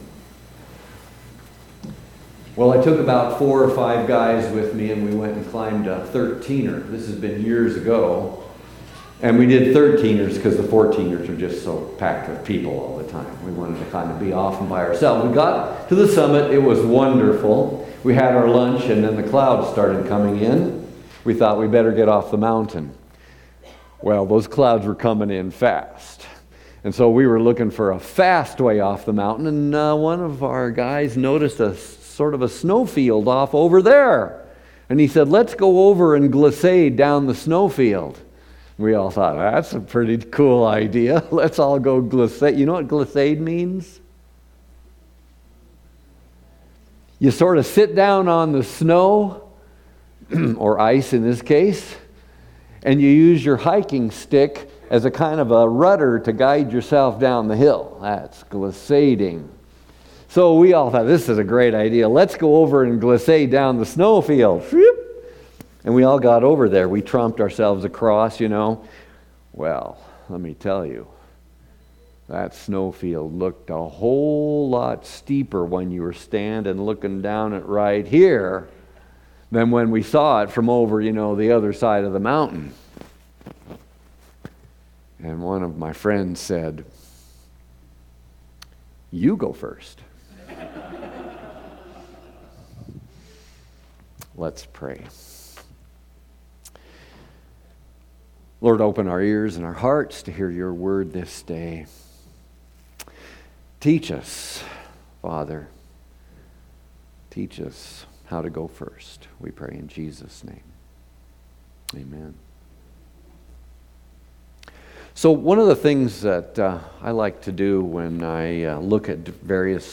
Seventh Sunday after Epiphany&nbsp